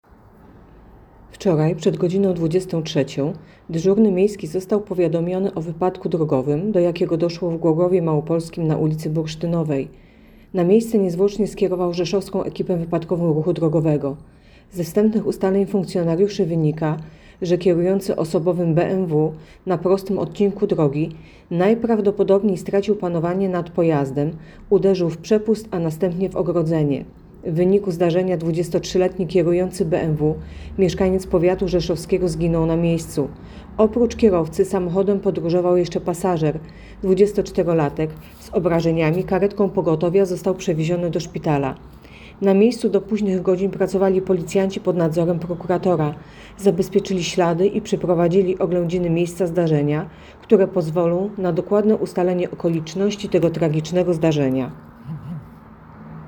Opis nagrania: Nagranie tekstu: Tragiczny wypadek w Głogowie Małopolskim. Nie żyje kierujący bmw.